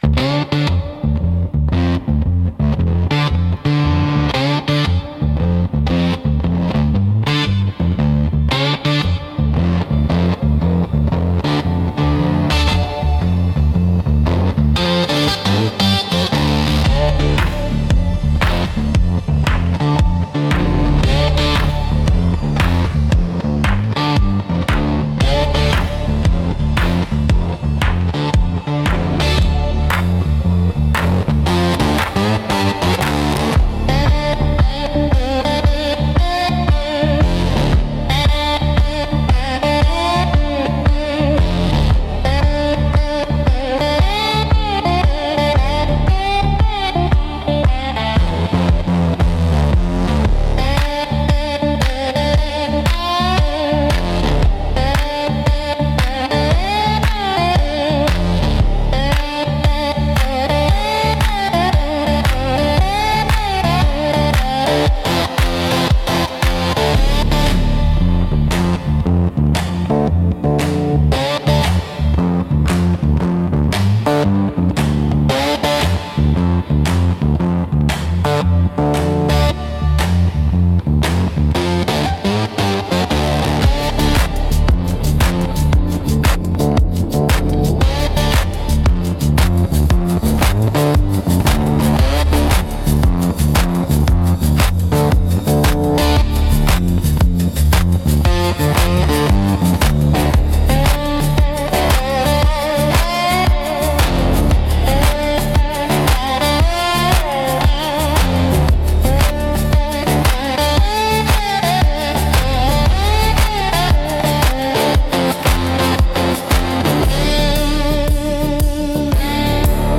Instrumental - Eerie Porch Lights